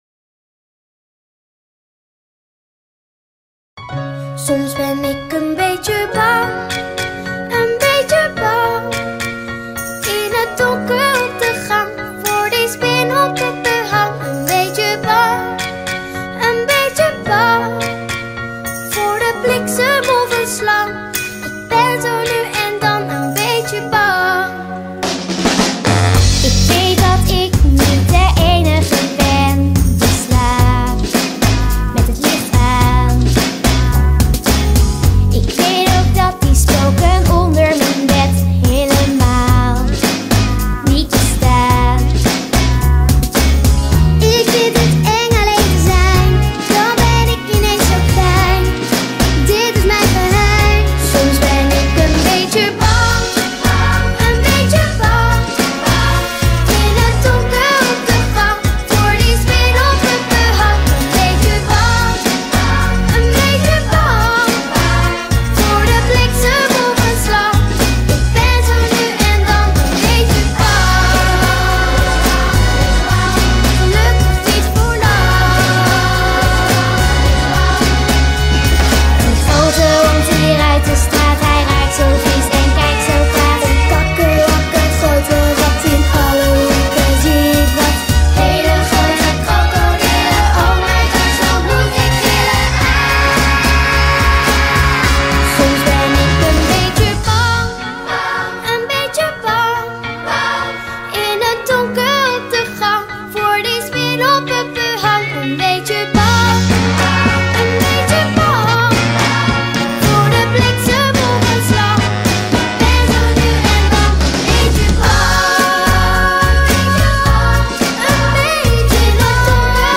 Tijdens dit experimenteermoment kan je het lied: 'een beetje bang' (zie bijlage) opzetten...